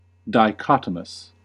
Ääntäminen
Synonyymit forked bipartite Ääntäminen US RP : IPA : /daɪˈkɒt.ə.məs/ US : IPA : /daɪˈkɑːt.ə.məs/ Haettu sana löytyi näillä lähdekielillä: englanti Käännöksiä ei löytynyt valitulle kohdekielelle. Määritelmät Adjektiivit Dividing or branching into two pieces.